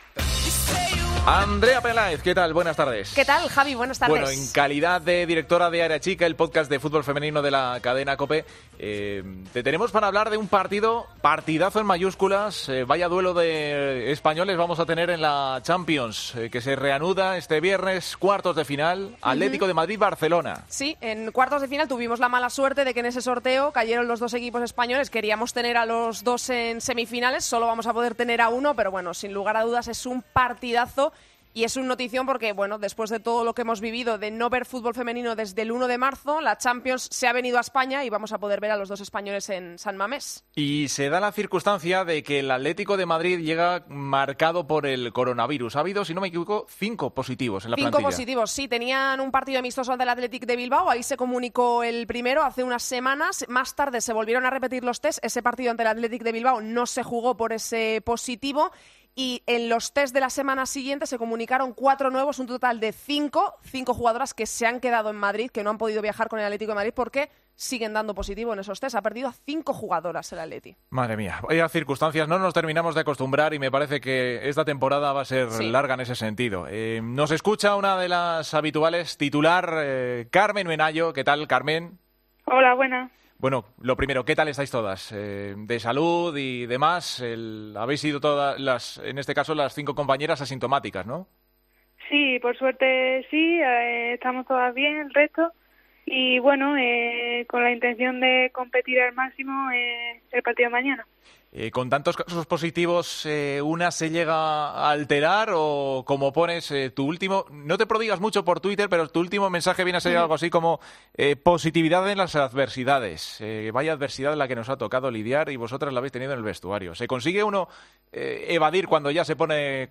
Hablamos con la jugadora del Atlético Femenino del partido de este viernes de la Champions ante el Barcelona.